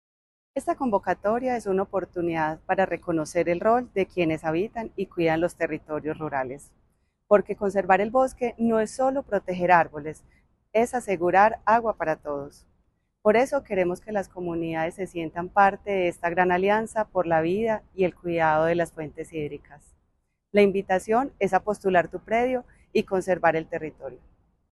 Audio Declaraciones de la secretaria (e) de Medio Ambiente, Elizabeth Coral Duque Se trata de un esfuerzo de la Alcaldía de Medellín por fortalecer la conservación ambiental en territorios sensibles al cambio climático y al deterioro del recurso hídrico.
Audio-Declaraciones-de-la-secretaria-e-de-Medio-Ambiente-Elizabeth-Coral-Duque.mp3